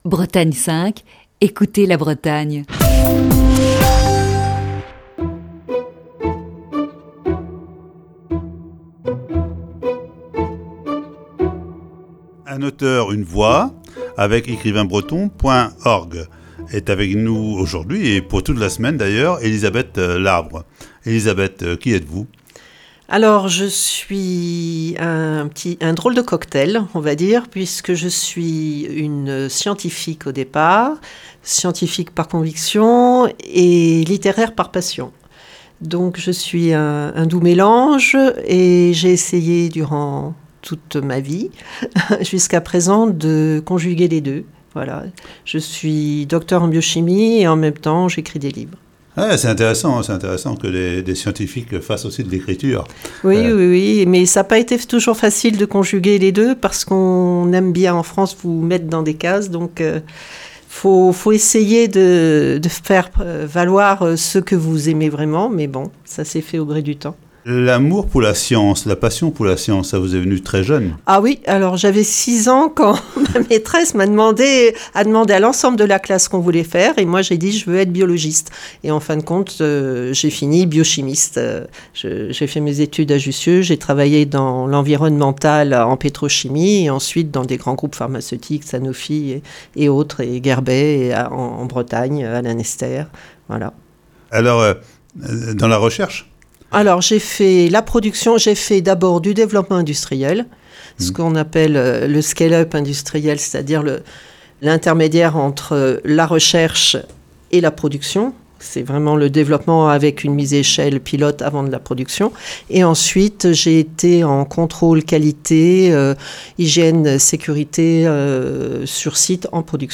Voici ce lundi, la première partie de cet entretien.